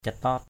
/ca-tɔ:t/ (d.) đống, bãi = tas. cataot aih c_t<T =aH bãi cứt = tas d’excrément. daok sa cataot _d<K s% c_t<T ngồi một đống (ngồi yên) = se tenir assis...